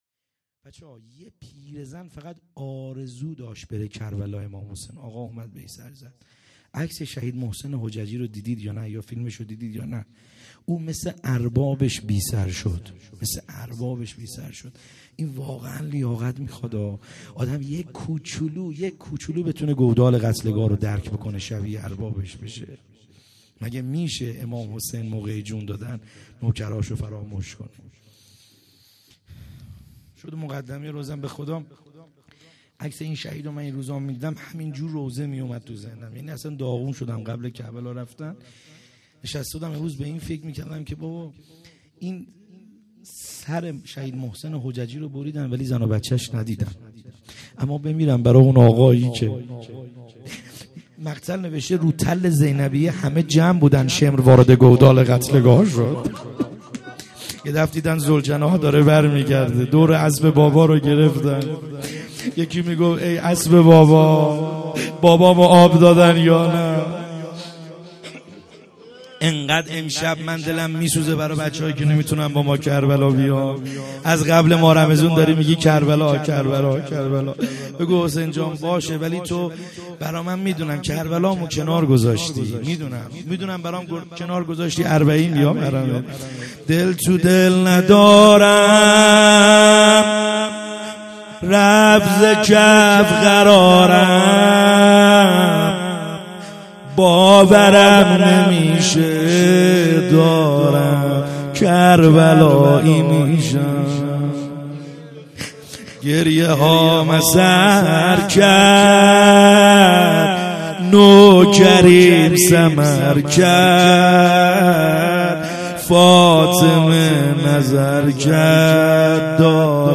خیمه گاه - بیرق معظم محبین حضرت صاحب الزمان(عج) - روضه | حضرت رقیه سلام الله علیها